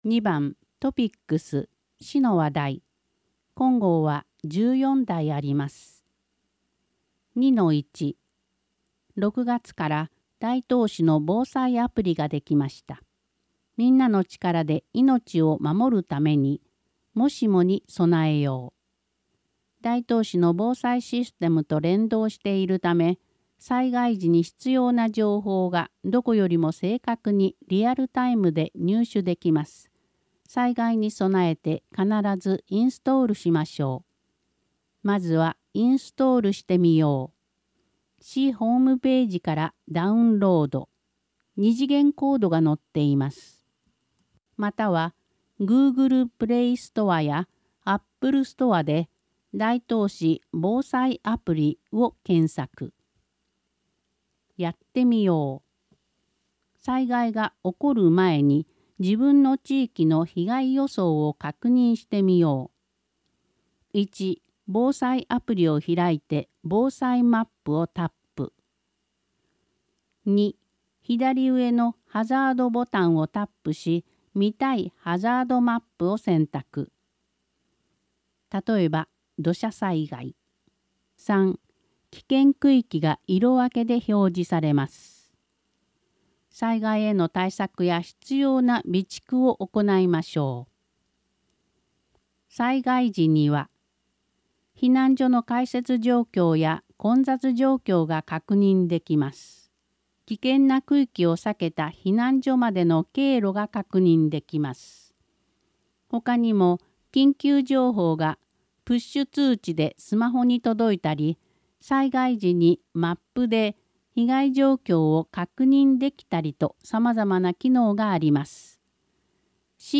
声の広報「だいとう」令和5年6月号を掲載しています。